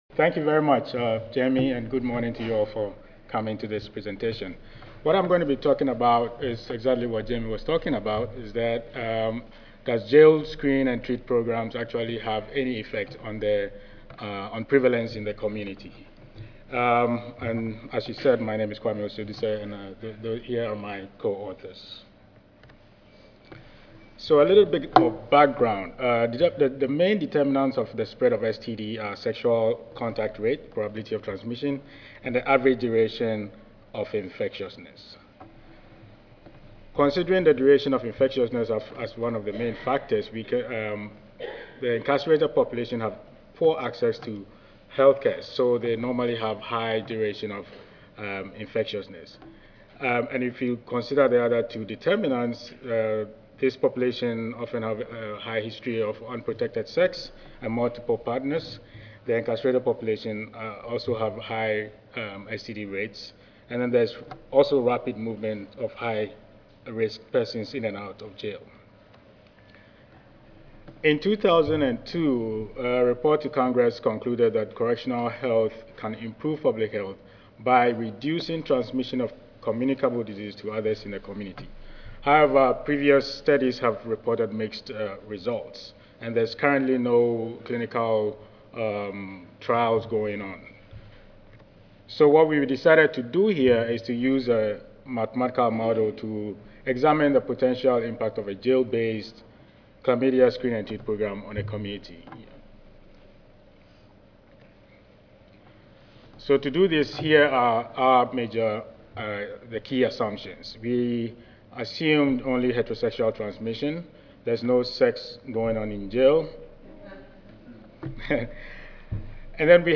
Grand Ballroom A (M4) (Omni Hotel)
Audio File Recorded presentation